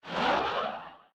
tweet_send.ogg